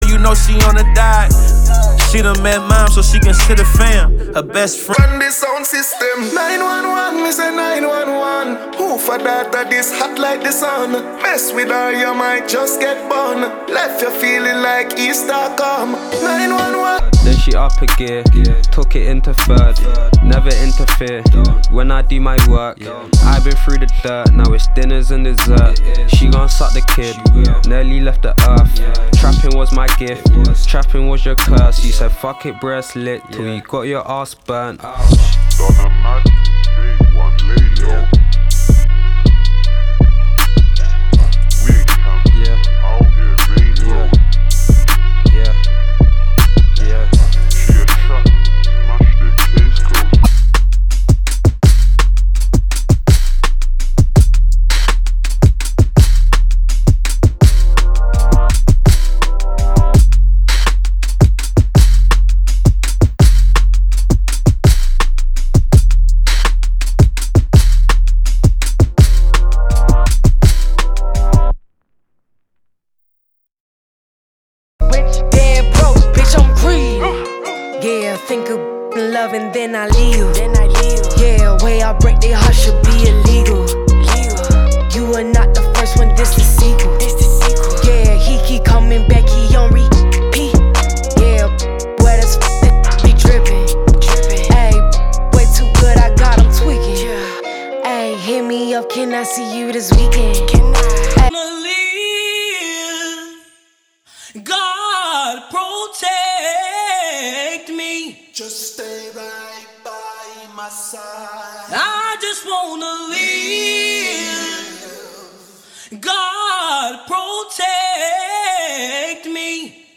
Classical